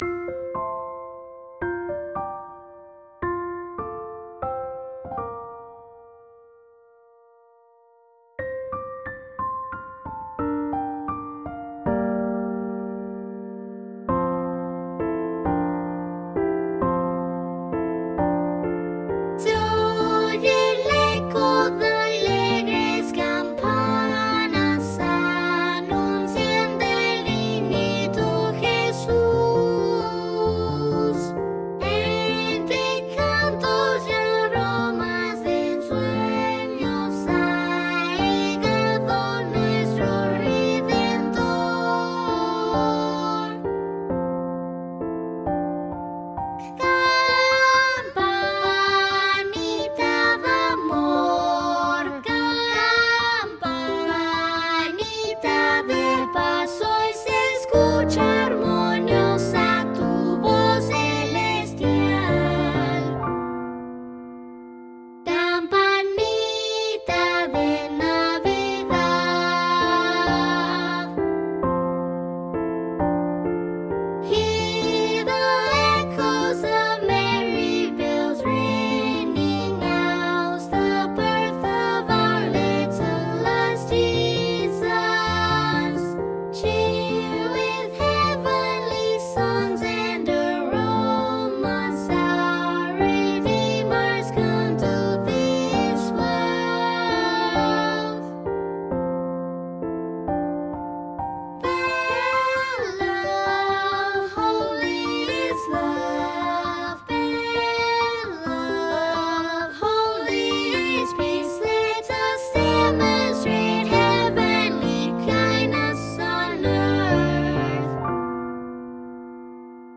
SA + Piano 2’20”
SA, Piano